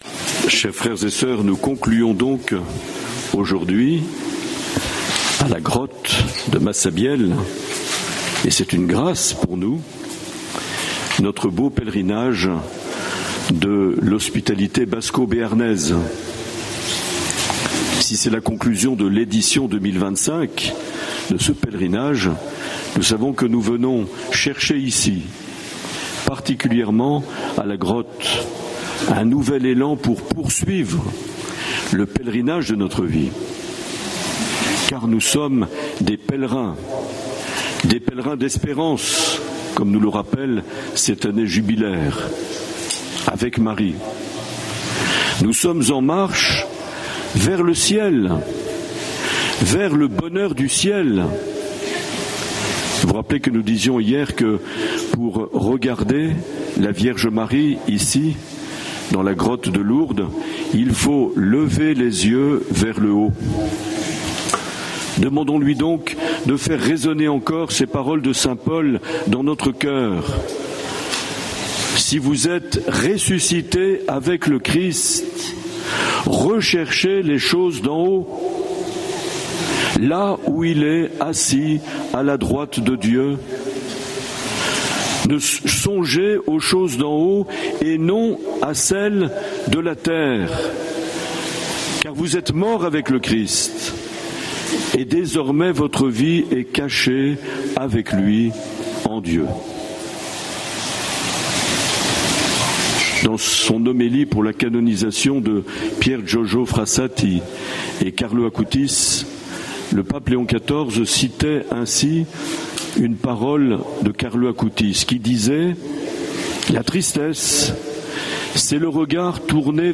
22 septembre 2025 - Pèlerinage diocésain à Lourdes
Homélie de Mgr Marc Aillet